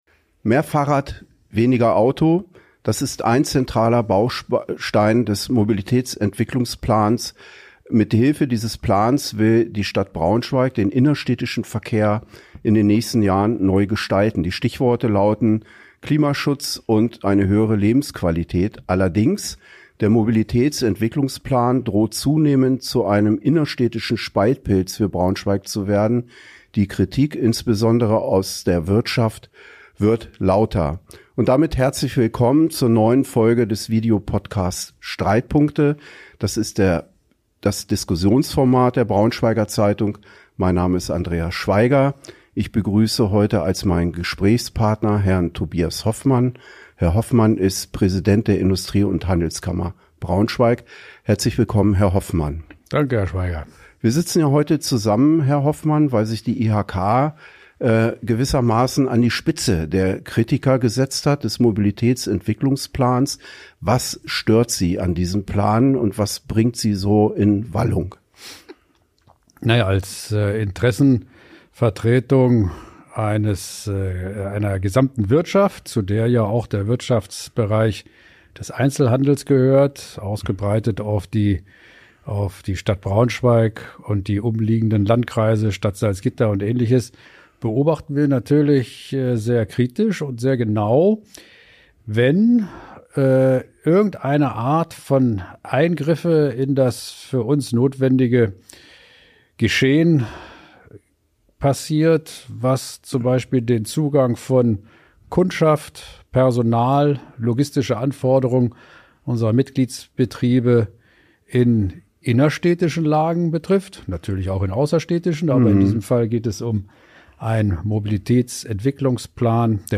Wirtschaft kritisiert Velorouten in Braunschweig ~ Streitpunkte – der Diskussions-Podcast der Braunschweiger Zeitung Podcast